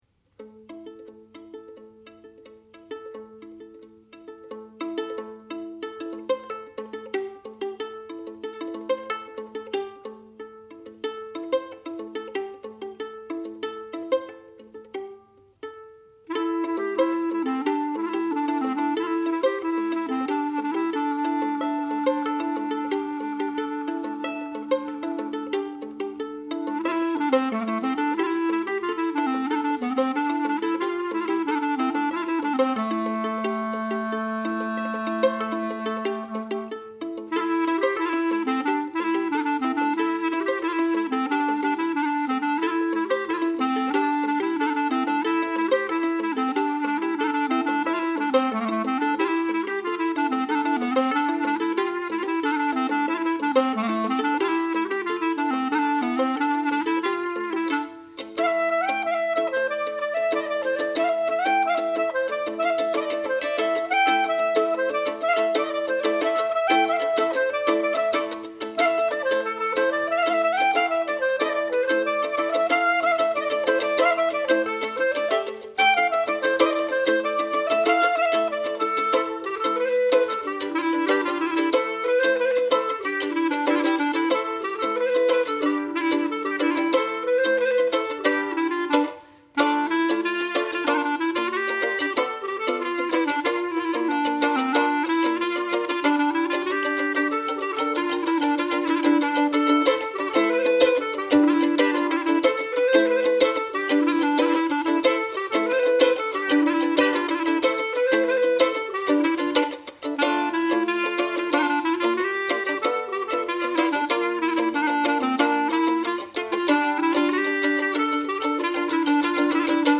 clarinette
violon
Fest-Noz